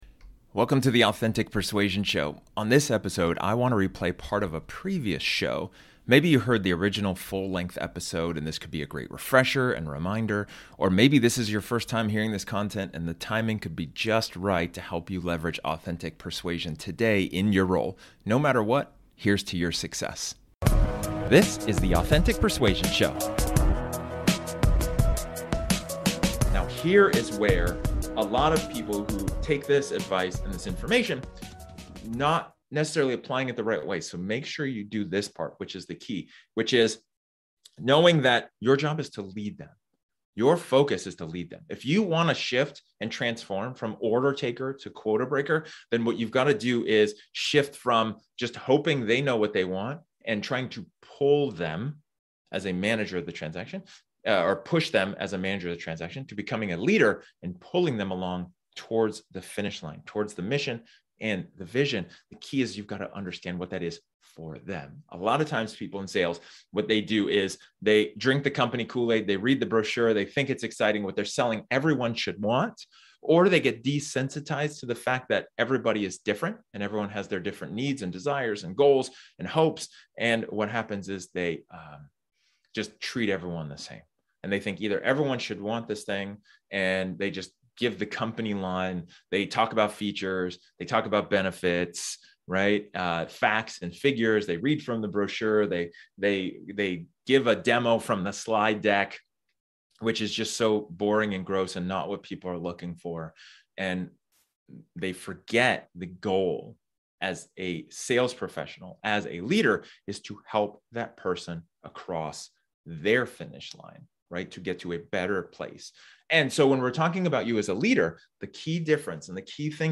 This episode is an excerpt from one of my training sessions where I talk about the question: "Are you being a Leader?"